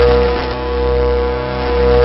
suitcharge1.wav